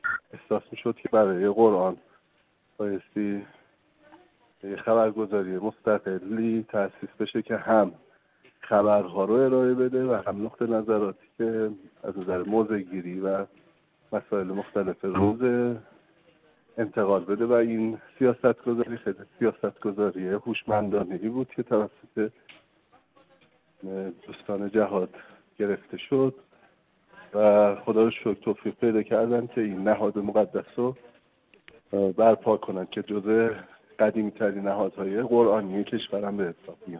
وی در گفت‌و‌گو با خبرنگار ایکنا گفت: هر نهادی با در نظر گرفتن مجموعه‌ای از انگیزه‌ها و اهدافی که برای آن تعیین می‌شود، شکل می‌گیرد و ایجاد سازمان قرآنی دانشگاهیان کشور از سوی جهاددانشگاهی براساس یک احساس نیاز، اقدامی مؤثر و قابل ستایشی بود.